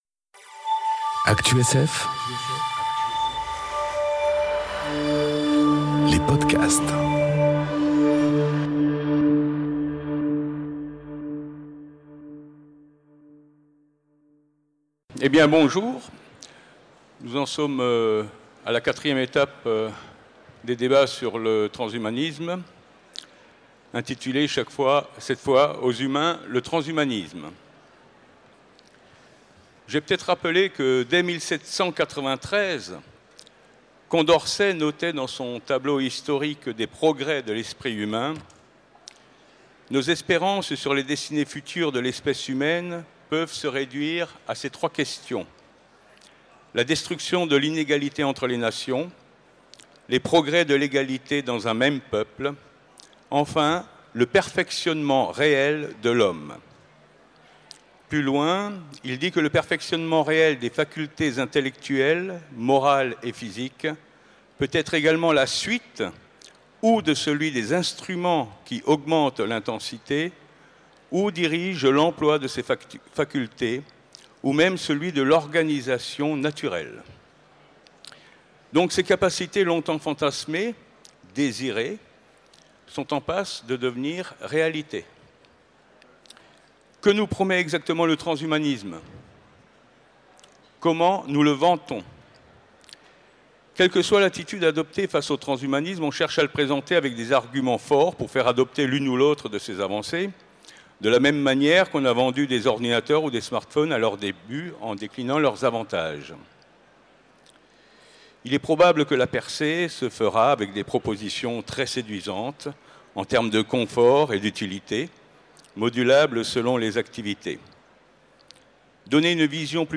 Conférence Transhumanisme 4 : Aux humains, le transhumanisme enregistrée aux Utopiales 2018